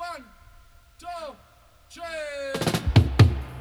134-FILL-DUB.wav